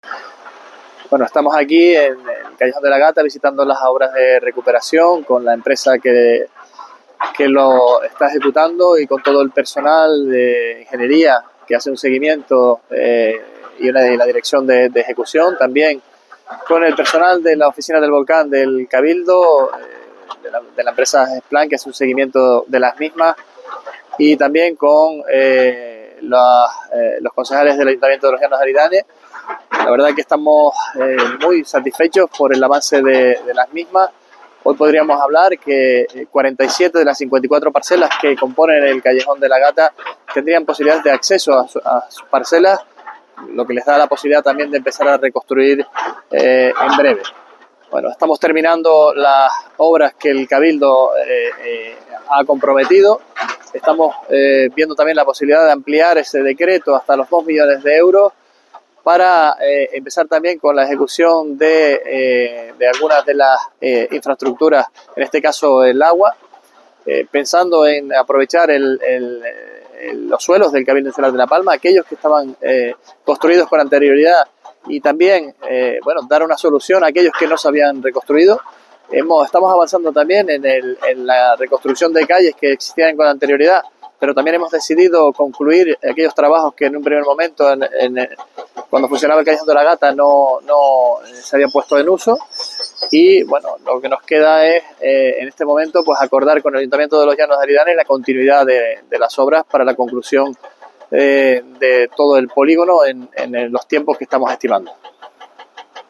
Declaraciones Sergio Rodríguez Callejón de la Gata audio.mp3